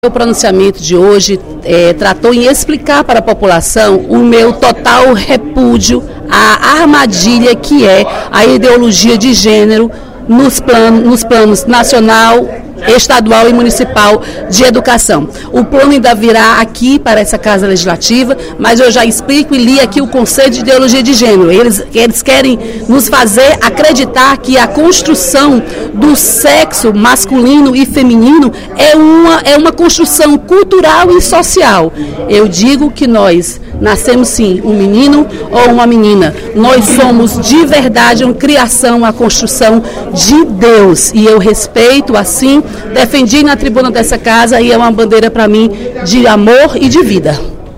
A deputada Dra. Silvana (PMDB) comentou, no primeiro expediente da sessão plenária da Assembleia Legislativa desta sexta-feira (26/06), o debate sobre modificações no Plano Municipal de Educação (PME), votado nesta terça-feira (23/06), na Câmara Municipal de Fortaleza, que faziam referência à diversidade sexual. Para a parlamentar, a inclusão de trechos no Plano que abordavam a homofobia e a sexualidade no ambiente escolar é uma tentativa de inserir ideologia de gênero nas escolas.